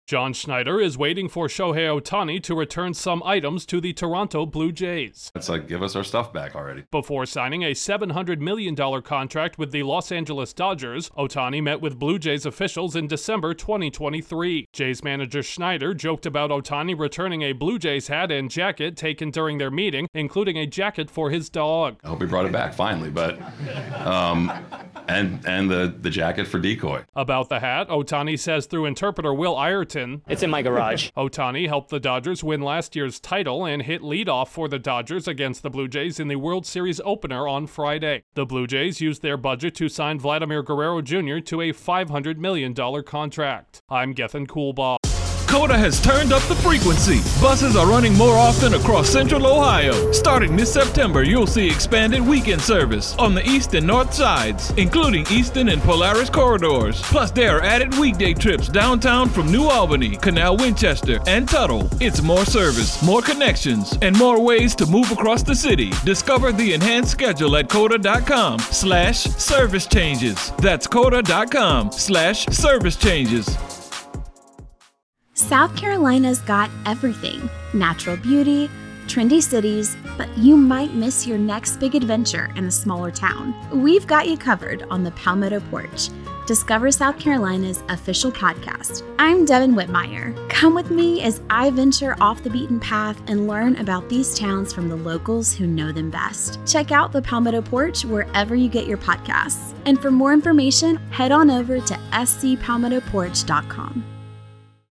Baseball’s biggest star is being asked to return some recruiting gifts he received from Toronto ahead of the World Series. Correspondent